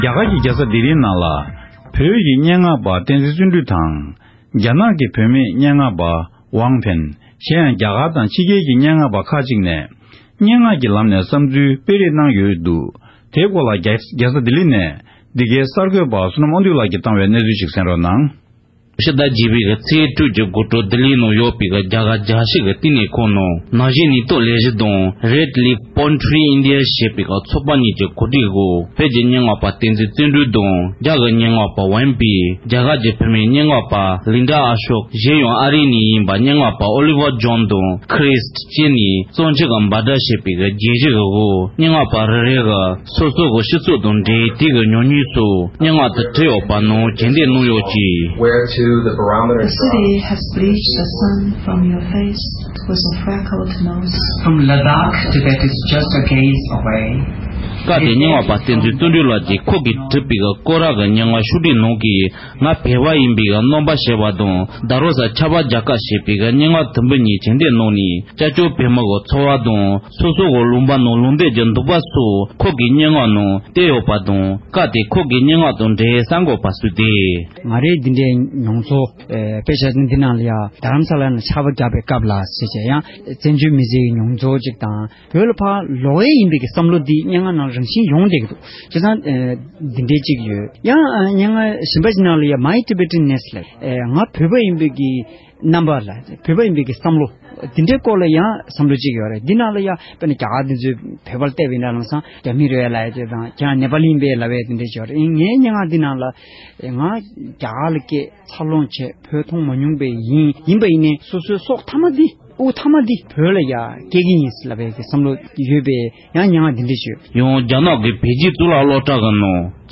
སྒྲ་གདངས་ཀྱི་གཙང་ཆུ་ཞེས་པའི་སྙན་ངག་གྱེར་འདོན།